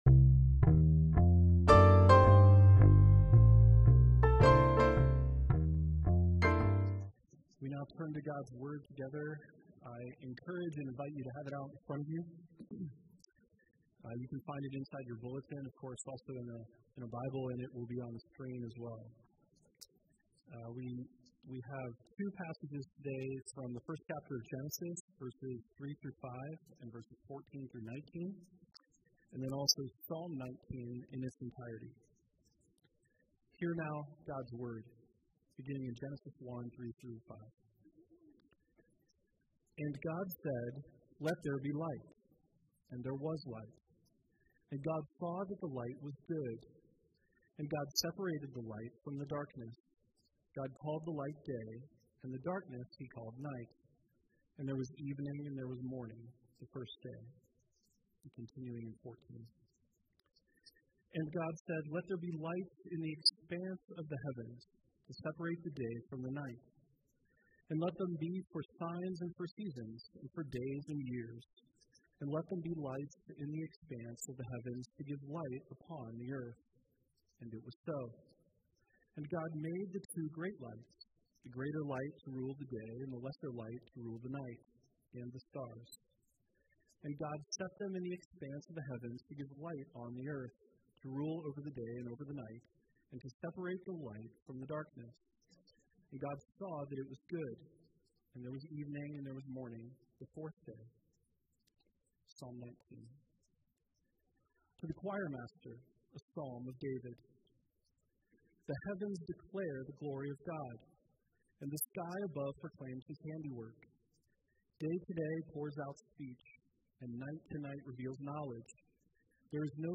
Passage: Genesis 1:3-5, 14-19; Psalm 19 Service Type: Sunday Worship